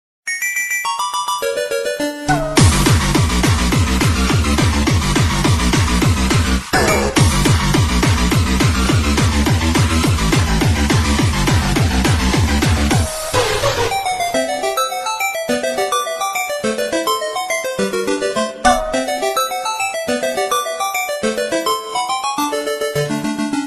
Catégorie Jeux